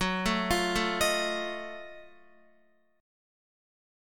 F#mM13 Chord
Listen to F#mM13 strummed